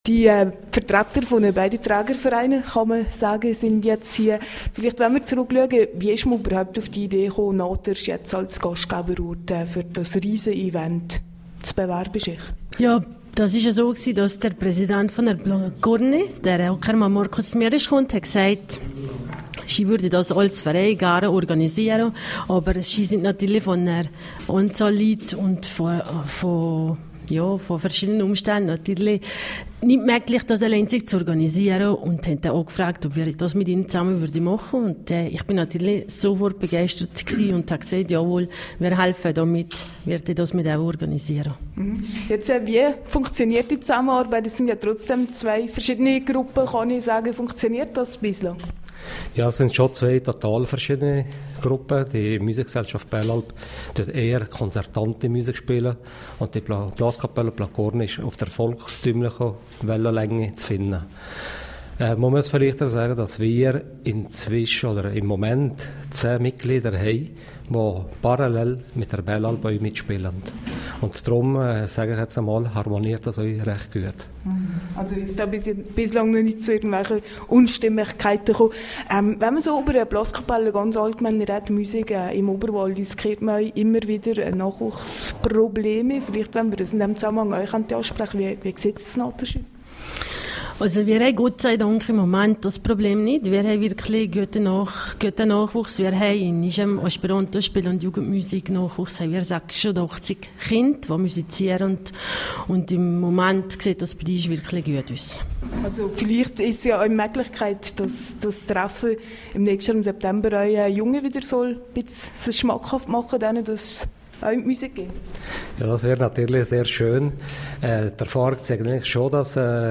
Audiobeitrag zum Thema Interview